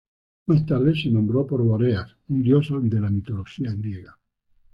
Pronounced as (IPA) /ˈdjos/